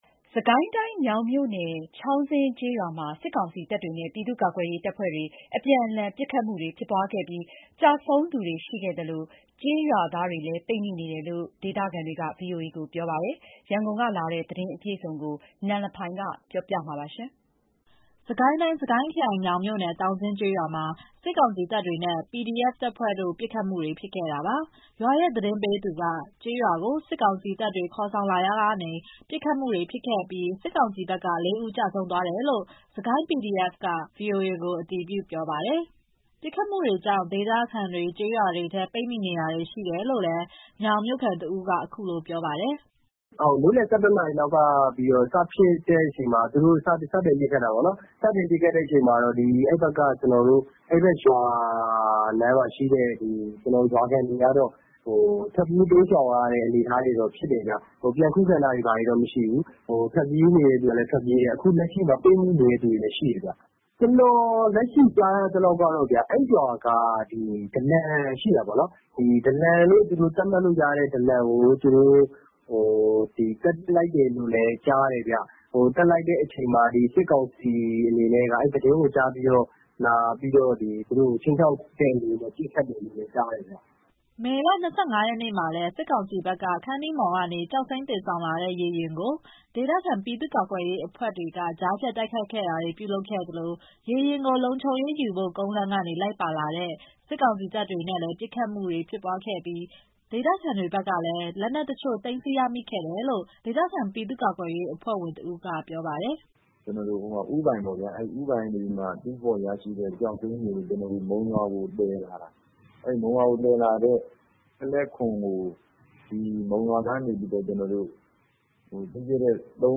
ပစ်ခတ်မှုတွေကြောင့် ဒေသခံတွေ ကျေးရွာတွေထဲမှာ ပိတ်မိတာတွေရှိနေတယ်လို့ မြောင်မြို့ခံတဦးက အခုလိုပြောပါတယ်။